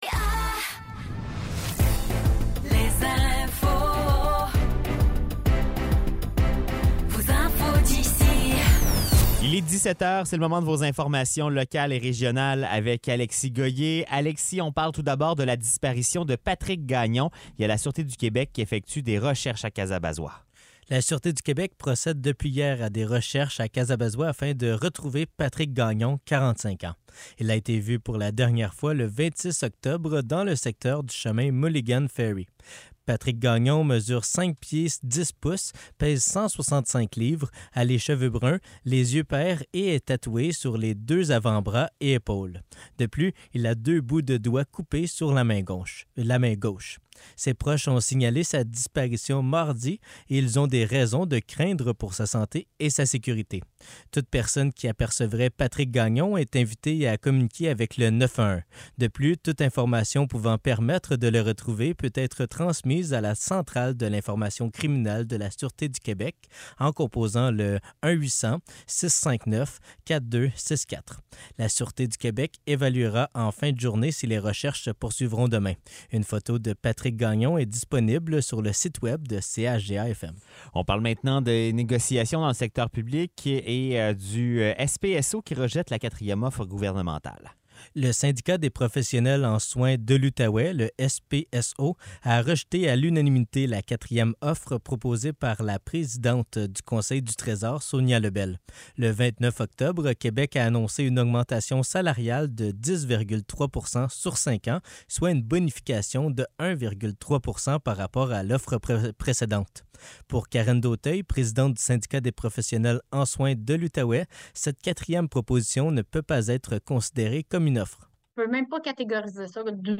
Nouvelles locales - 2 novembre 2023 - 17 h